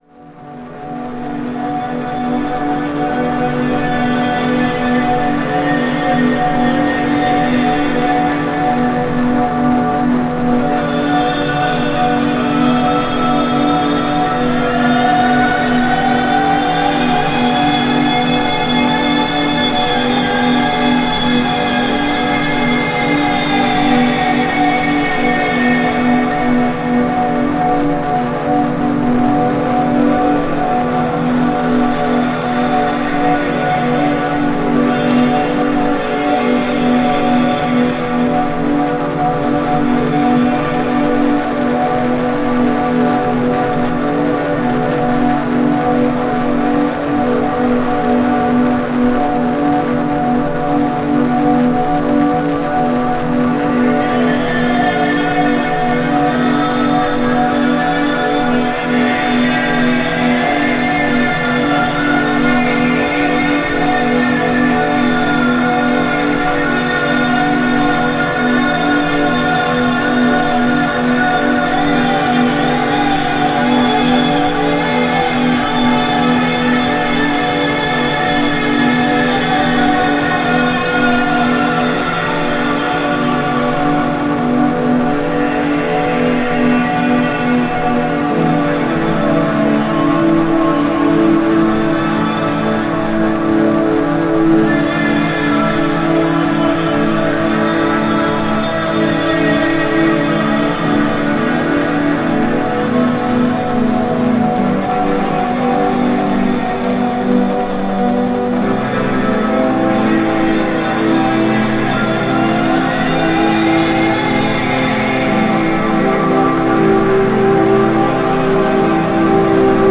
• Genre: Noise / Industrial / Experimental / Ambient